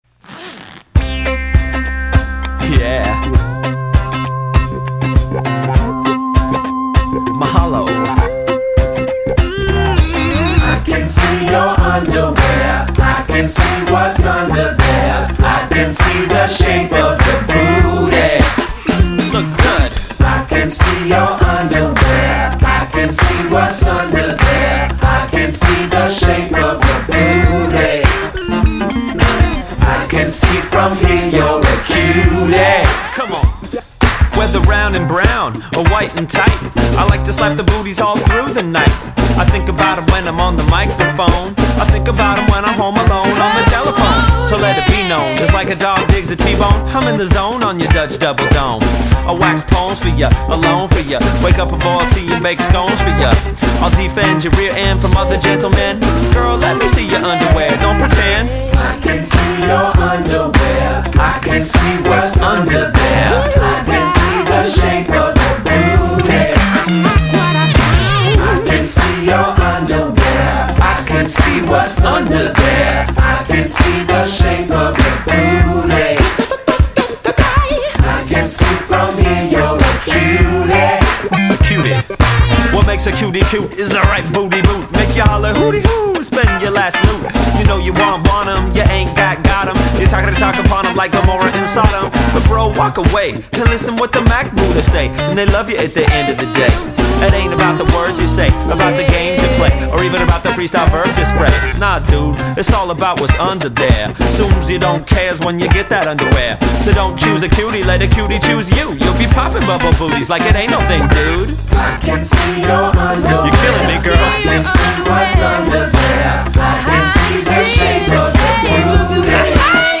R&B
crazy male ad-libs